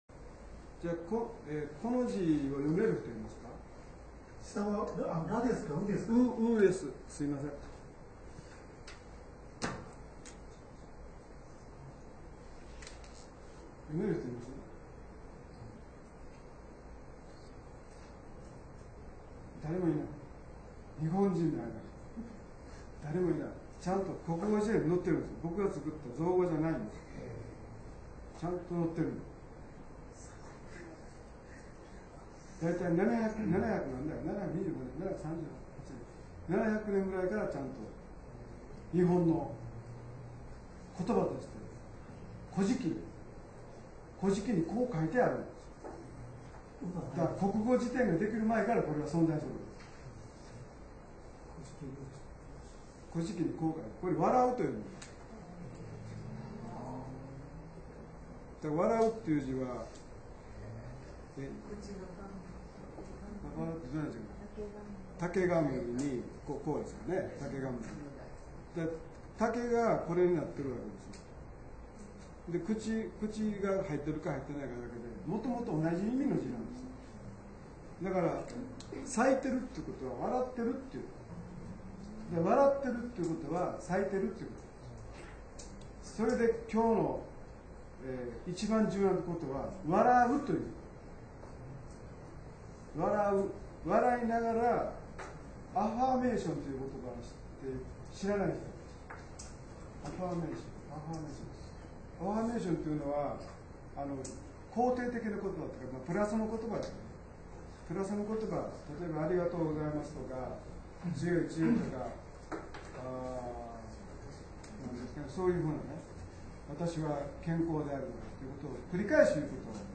合計収録時間　９６分３５秒　大阪なにわ幸い塾にて収録
音声の一部にノイズがございます。収録時のノイズですのでＣＤの不良品ではございませんことをご了承ください。